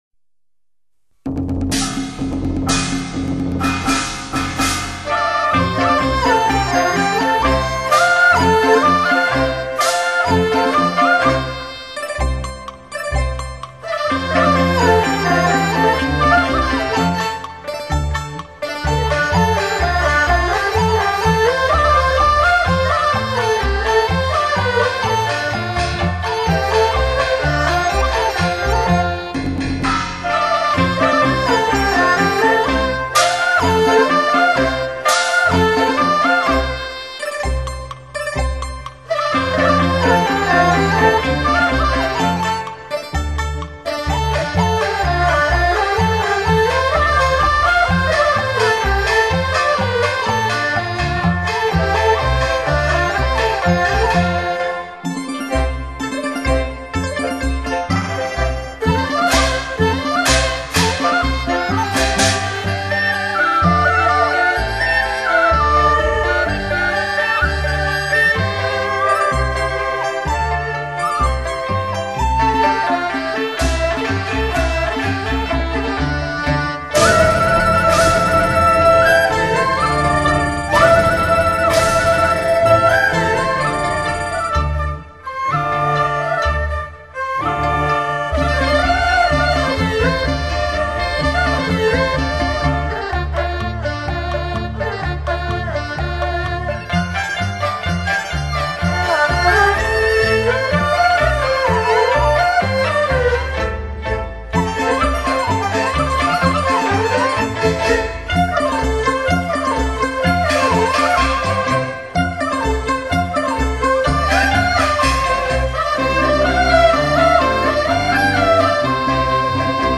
专辑歌手:纯音乐